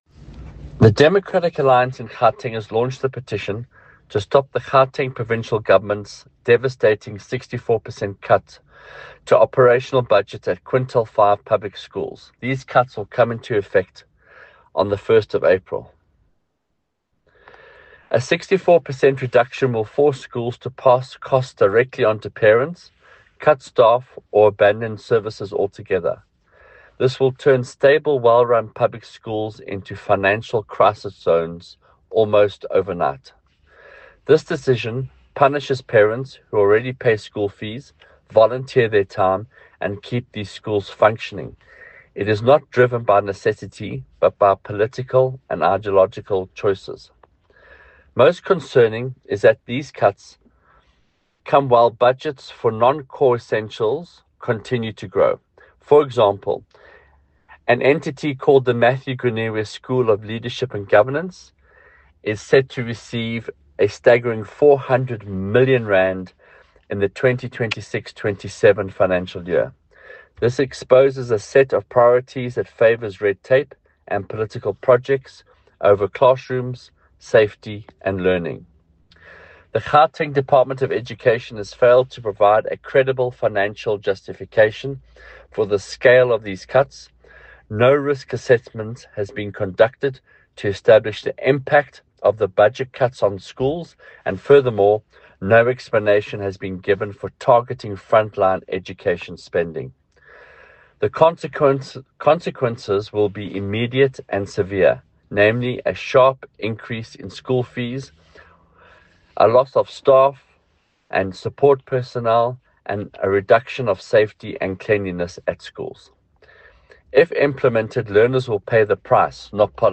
Issued by Michael Waters MPL – DA Gauteng Spokesperson for Education
soundbite by Michael Waters MPL